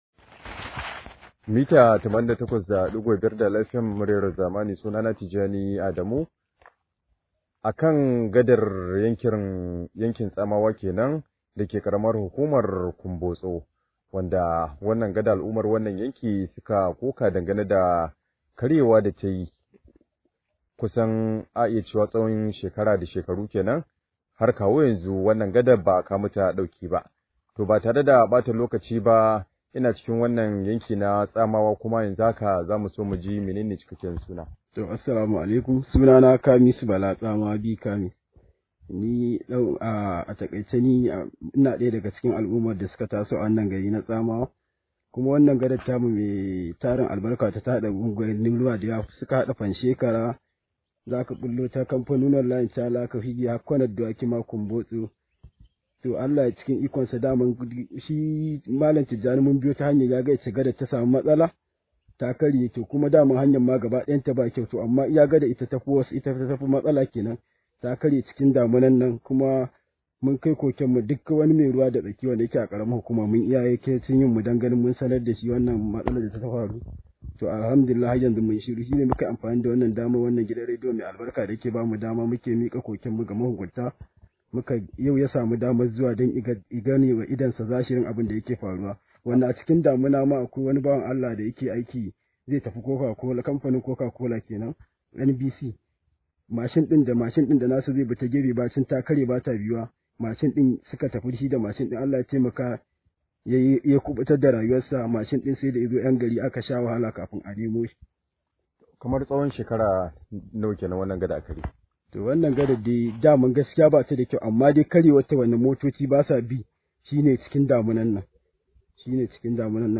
Rahoto: Mu na neman ɗauki akan karyewar Gada